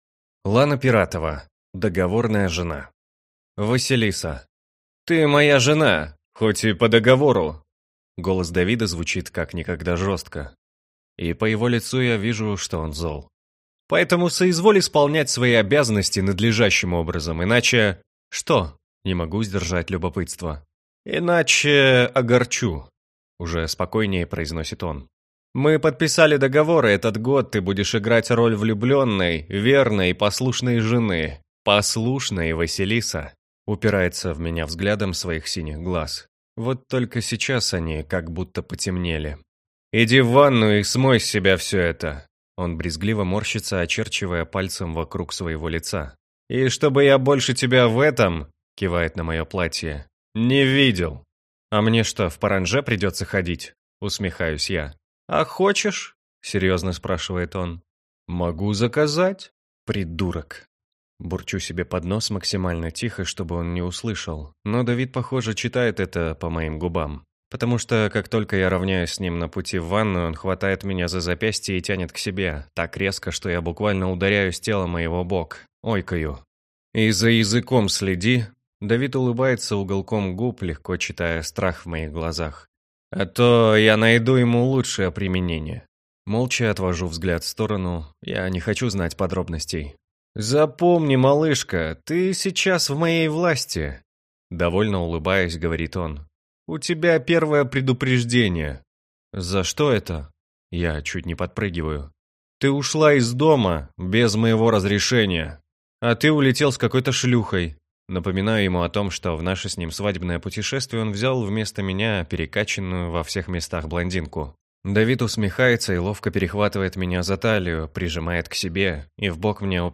Аудиокнига Договорная жена | Библиотека аудиокниг
Прослушать и бесплатно скачать фрагмент аудиокниги